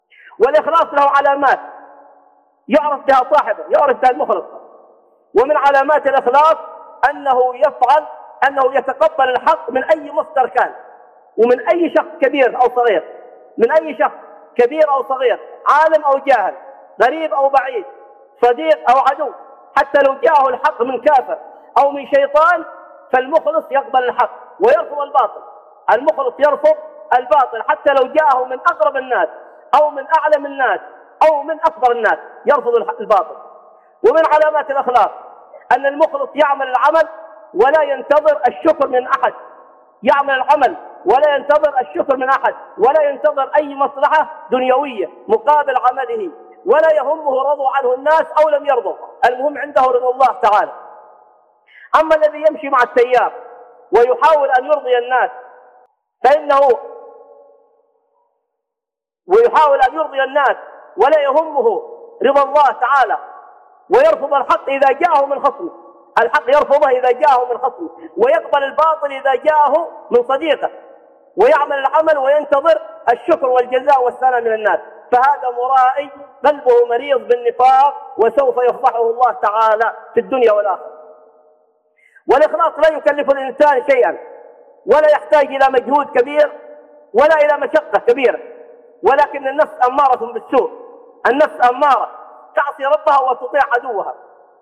من علامات المخلصين لله تعالى - خطب